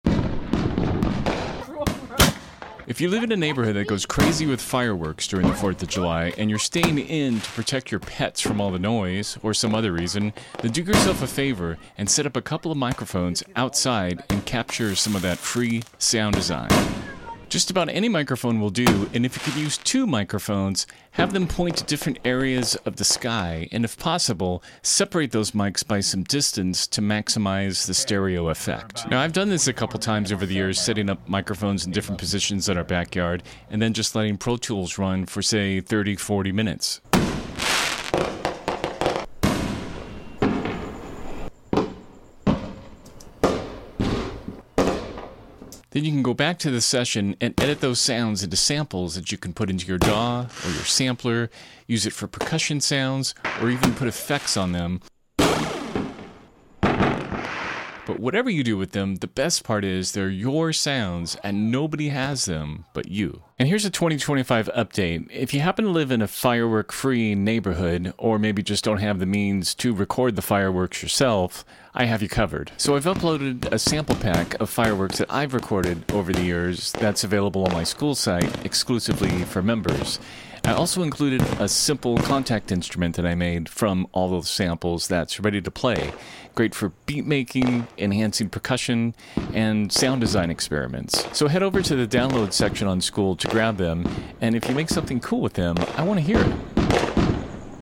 Fireworks make great raw material for sound design, percussion, and ambient textures.